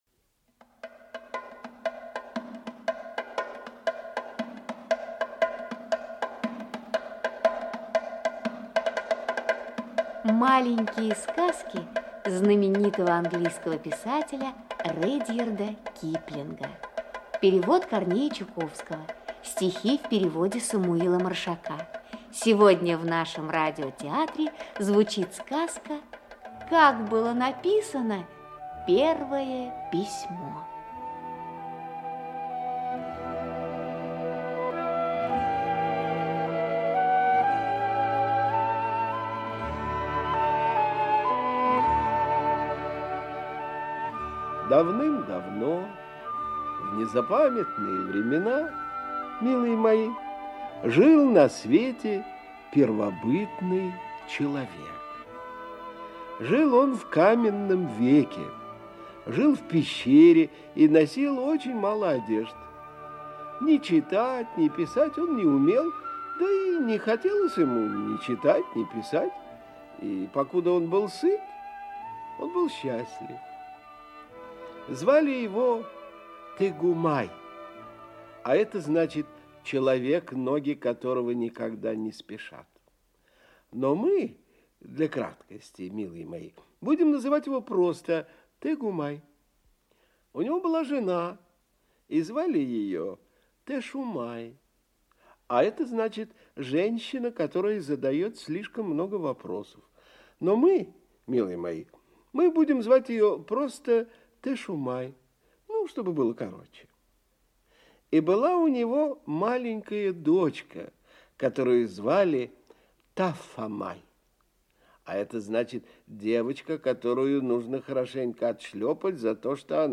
Аудиокнига Как было написано первое письмо | Библиотека аудиокниг
Aудиокнига Как было написано первое письмо Автор Редьярд Джозеф Киплинг Читает аудиокнигу Актерский коллектив.